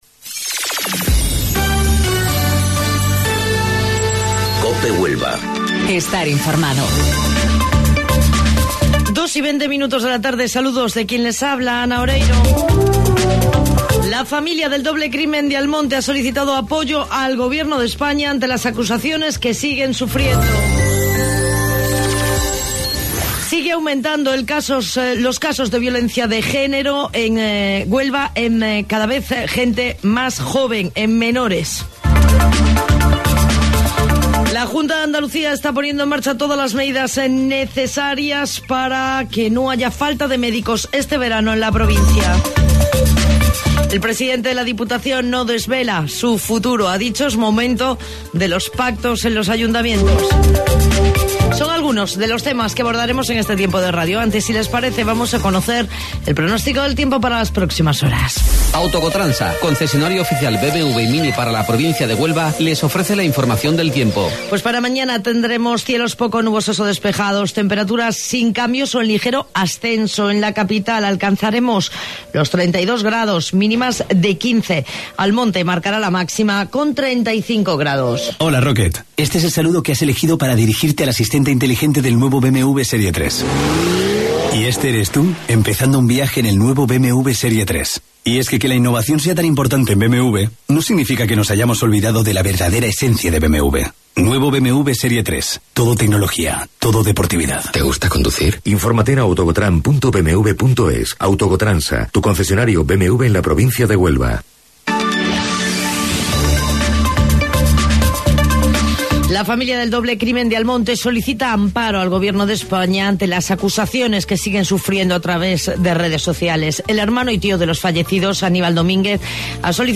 Mediodía Cope Huelva - Informativo 14:20 - 30 Mayo